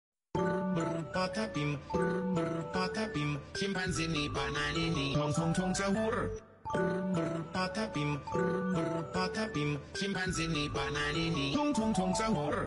Samsung Alarm